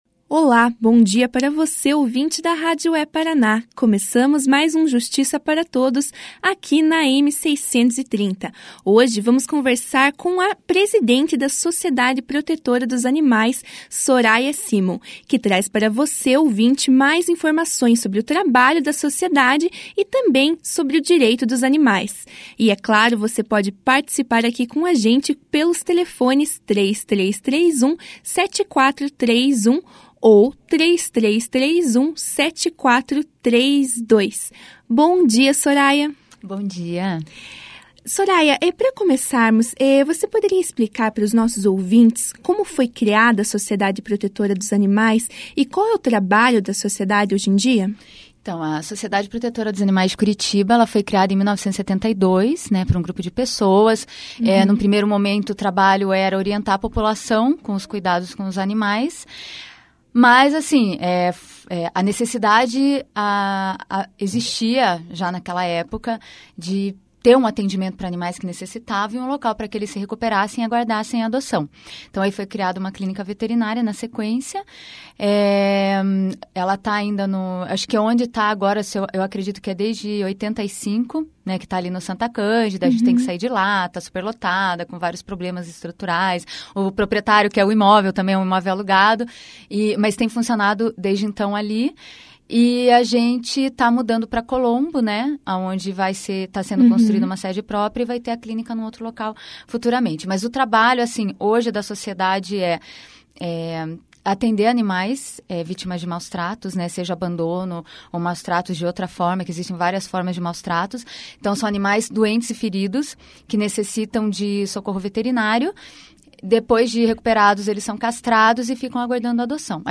O Direito dos Animais foi discutido hoje (9) no programa de rádio da AMAPAR, o Justiça para Todos.